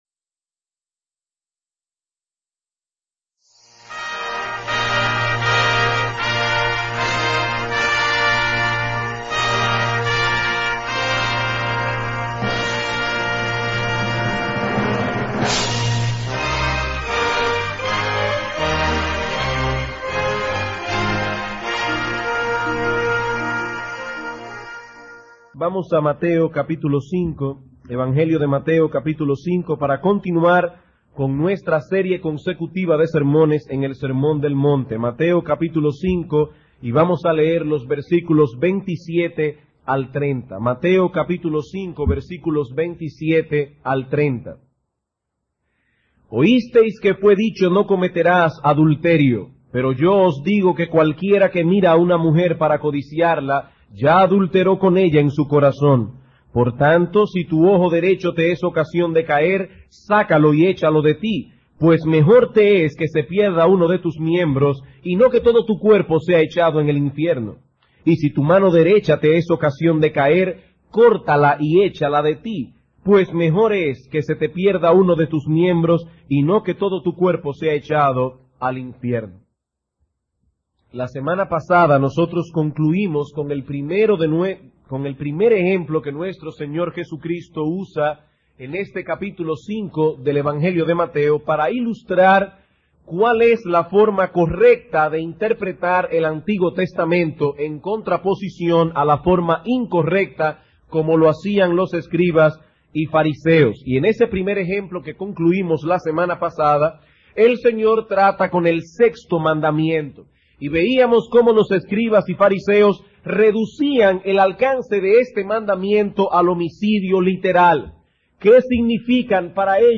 Estudio bíblico